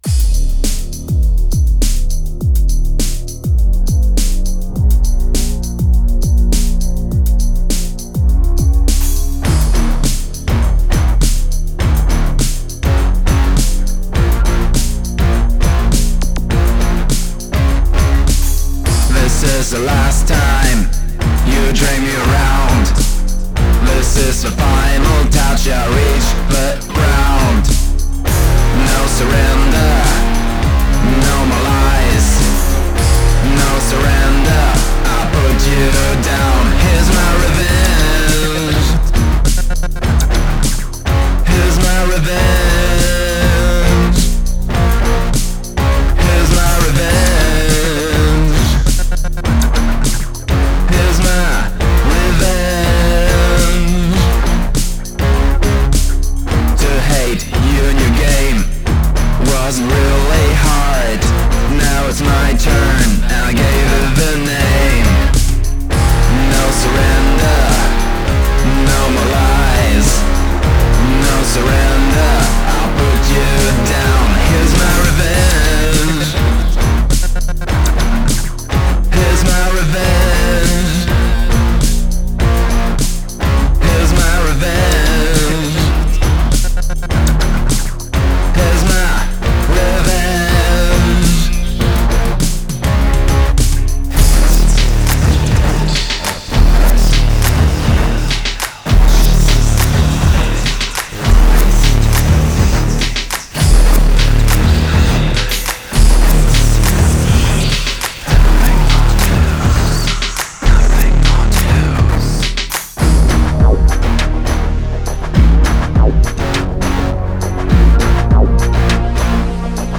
[102 BPM]